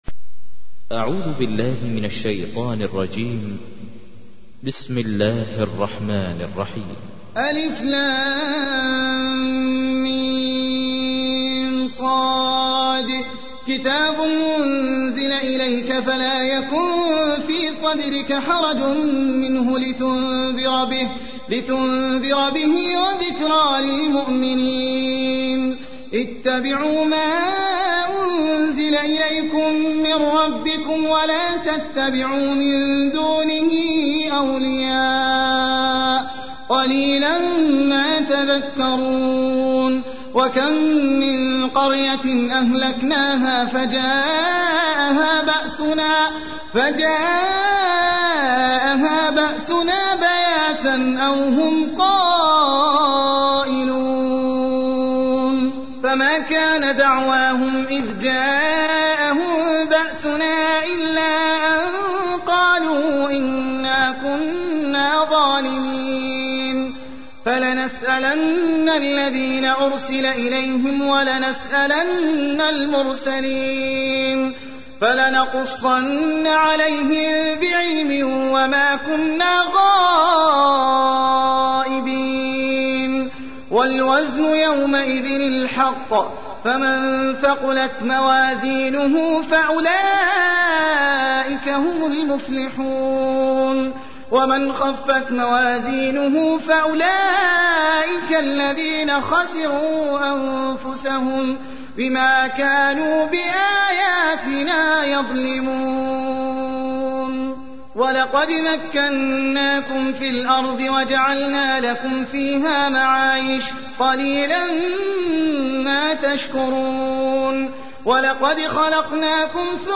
تحميل : 7. سورة الأعراف / القارئ احمد العجمي / القرآن الكريم / موقع يا حسين